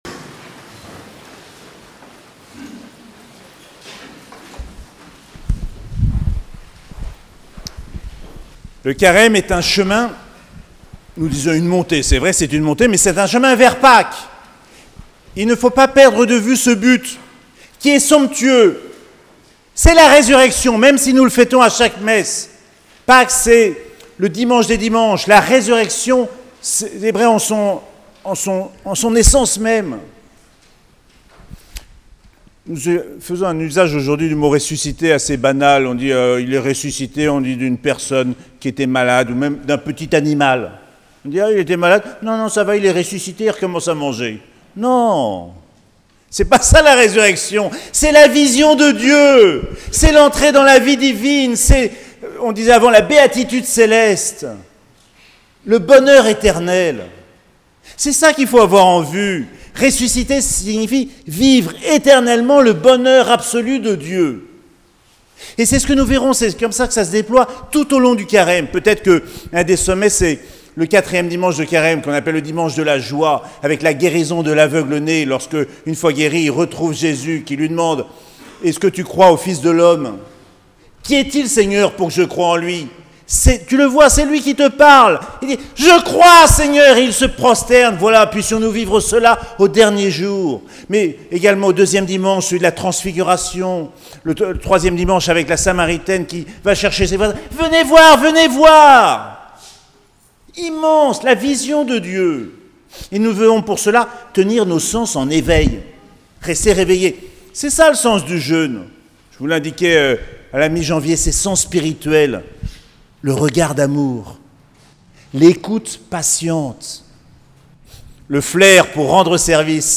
Mercredi des Cendres - 22 février 2023